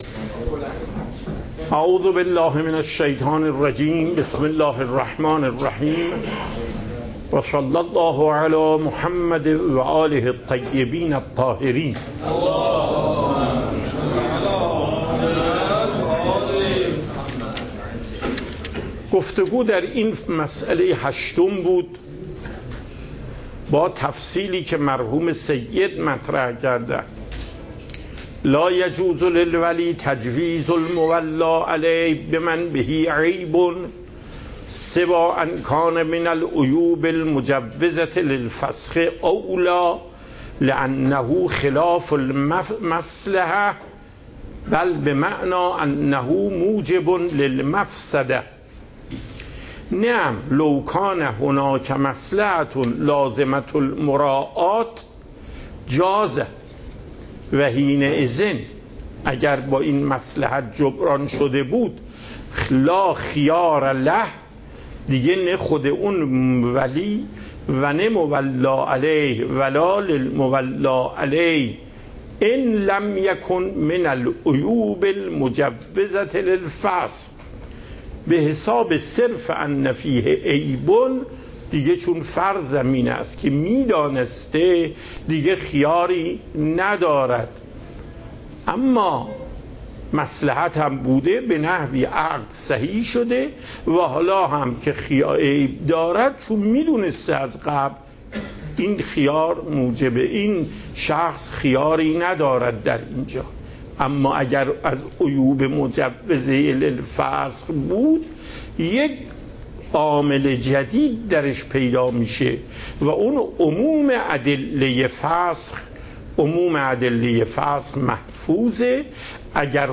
درس فقه آیت الله محقق داماد